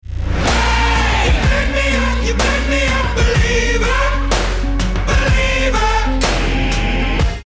alert-sound_BqX5w3C.mp3